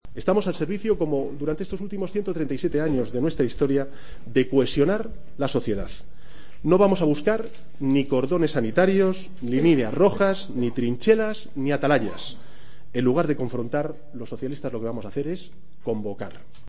AUDIO: En el Comité Federal de su partido